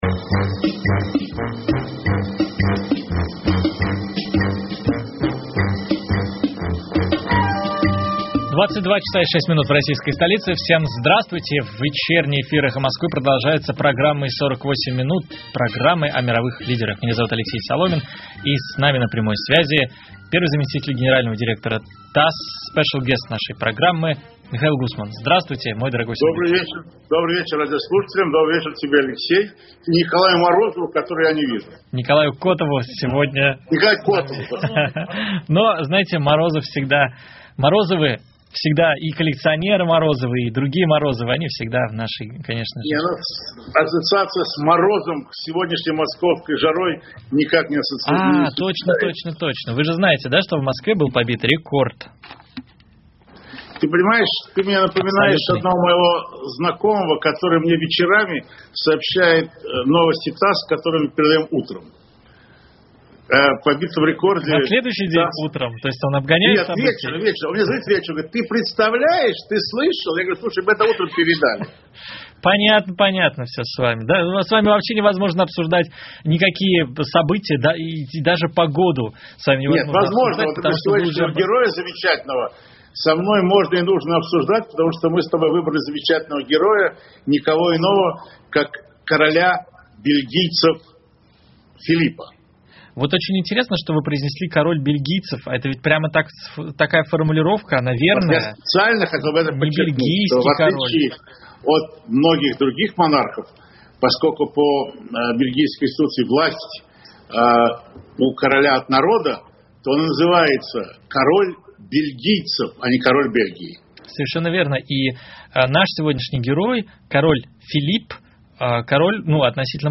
Вечерний эфир «Эха Москвы» продолжается программой «48 минут», программой о мировых лидерах.